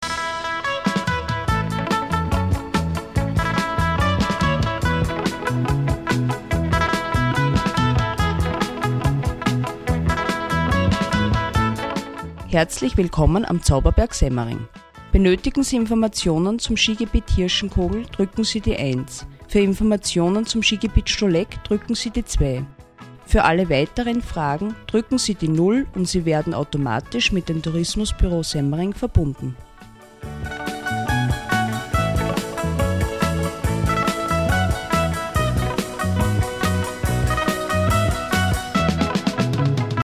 Telefonjingles
Die Ansagen bekommen Sie in verschiedenen Längen, mit verschiedenster Art Musik unterlegt, oder auch ganz ohne Musik, so dass es Ihren Ansprüchen gerecht wird.
Sie sind noch nicht überzeugt, dann hören Sie sich doch als Beispiel das Schneetelefon vom Schigebiet Semmering/Hirschenkogel an.